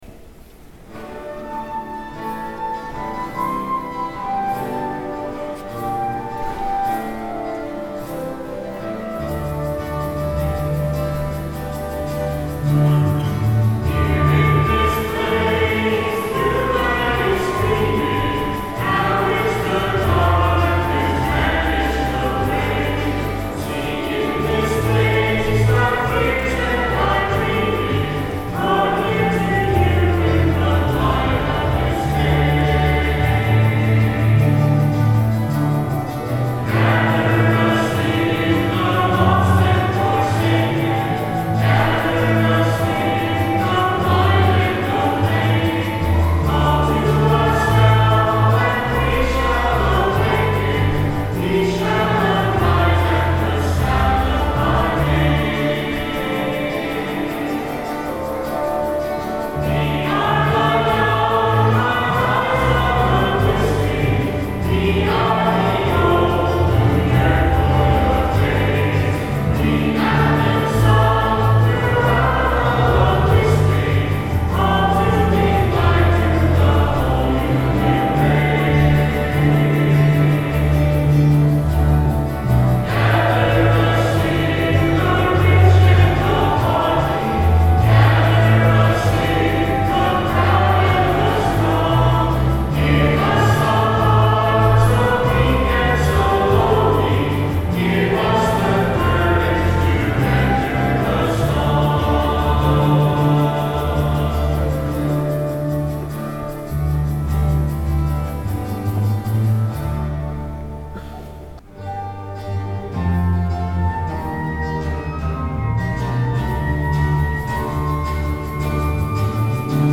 Music from the 10:30 Mass on Sunday, August 7, 2011 Note that all spoken parts of the Mass have been removed from this sequence. The Mass parts, however, have been included.
lead guitarist